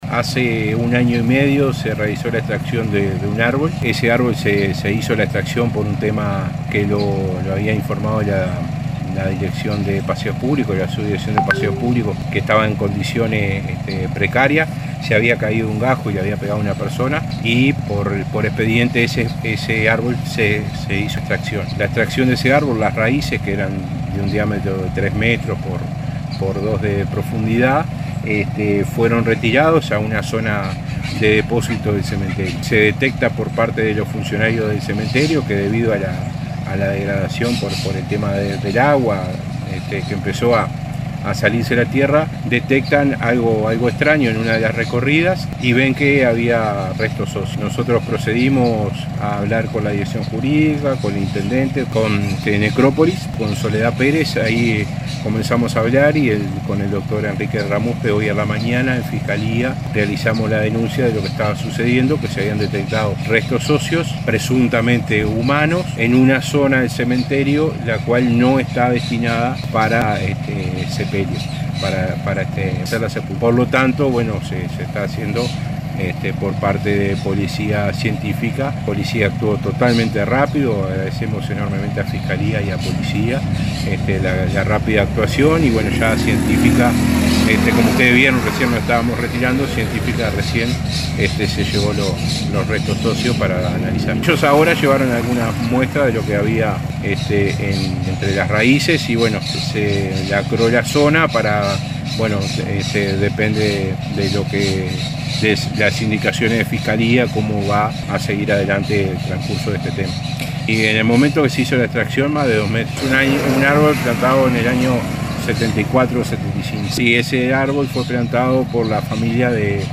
El alcalde de Carmelo, Pablo Parodi, brindó detalles sobre el procedimiento realizado por el Municipio y las actuaciones que se desarrollan tras el hallazgo en el cementerio local.